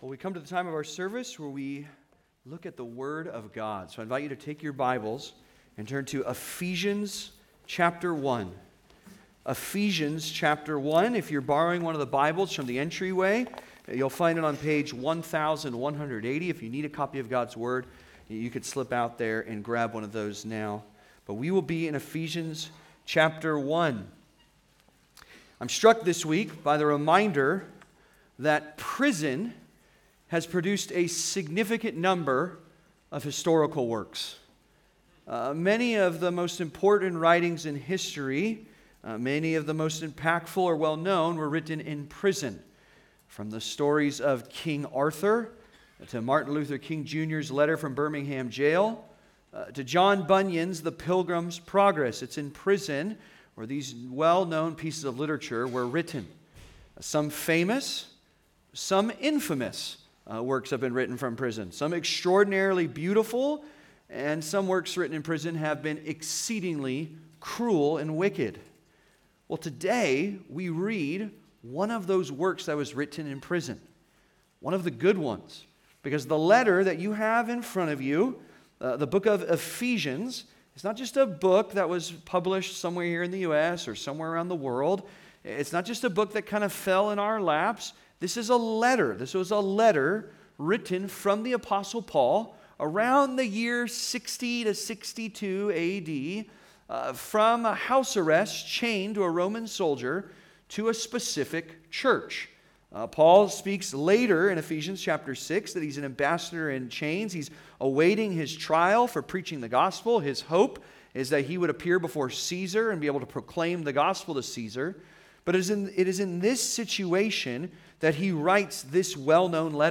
Chosen by the Father (Sermon) - Compass Bible Church Long Beach